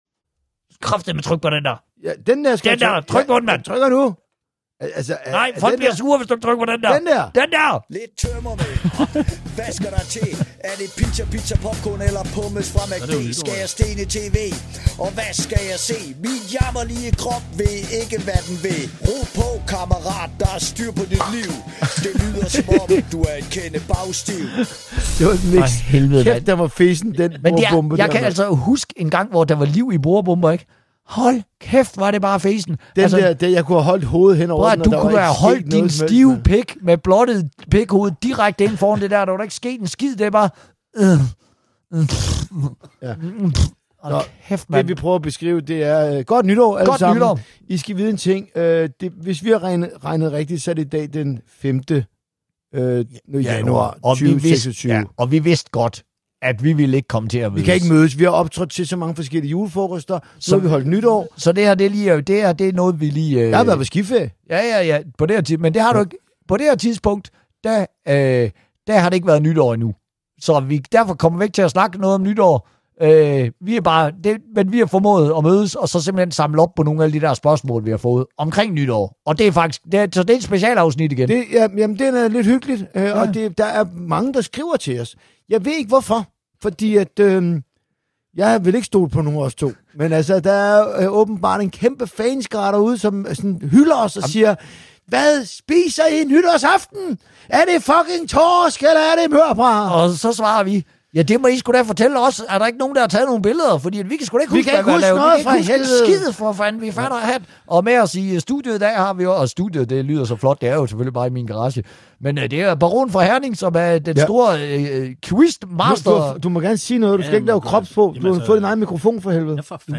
De to erfarende komikere Uffe Holm og Torben Chris er bagmændene i “Bagstiv”, som er et godt alternativ til DR-serien “Bagklog”. Podcasten “Bagstiv” ser sig selv som den sidste censurfri zone, og forsøger at ramme “dagen derpå”-stemning, hvor tanker til tider kommer ud af munden, før de er helt færdigbagt i hjernen.
Podcasten er ment som ren hygge samtale, som du kunne være en del af, hvis du vågnede op med Uffe og Torben dagen efter en brandert.